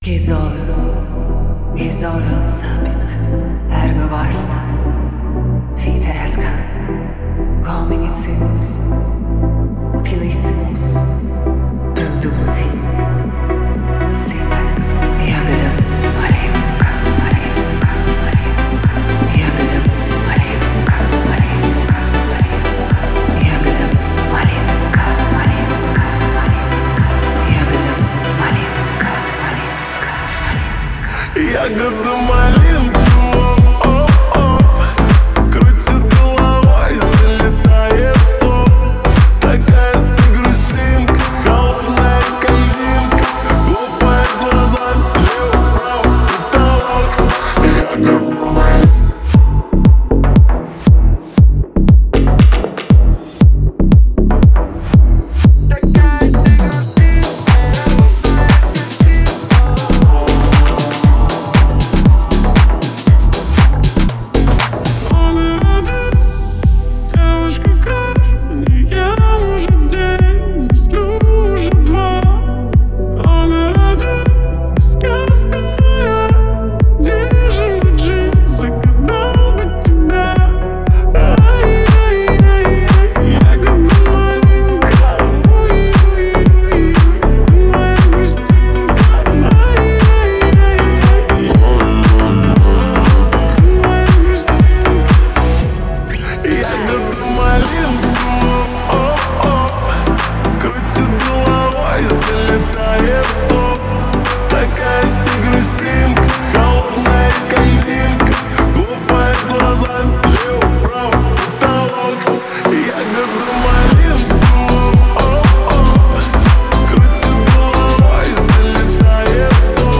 Файл в обменнике2 Myзыкa->Psy-trance, Full-on